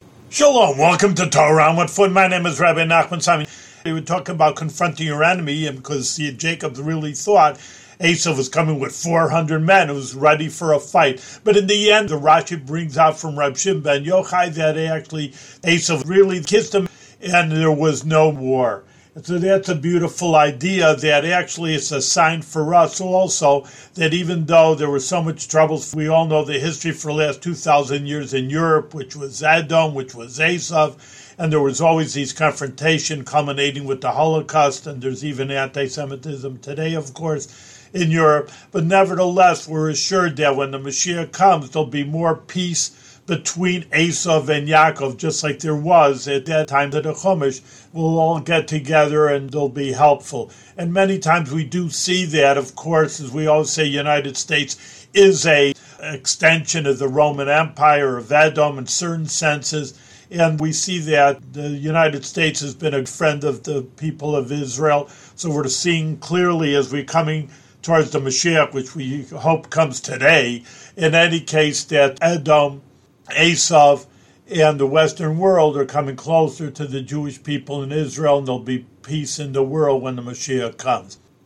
One-minute audio lessons on special points from weekly Torah readings in the Book of Genesis.